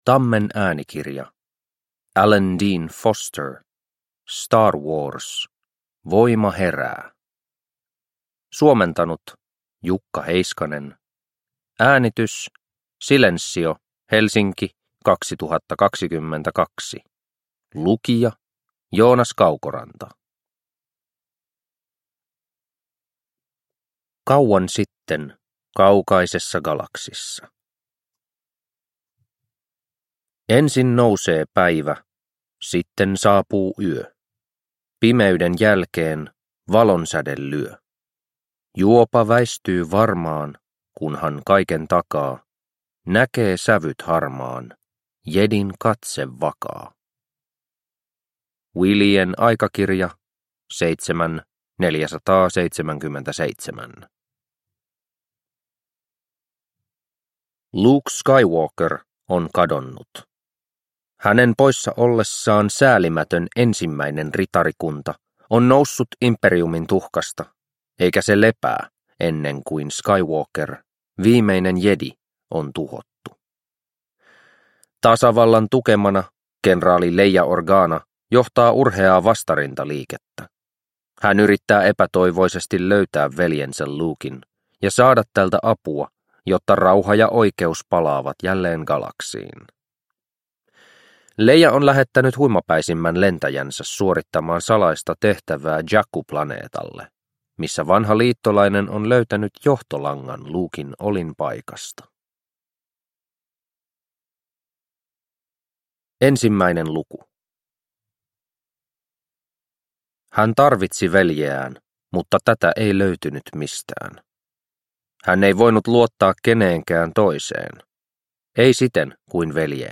Star Wars. Voima herää – Ljudbok – Laddas ner